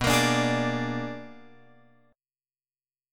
B Minor Major 13th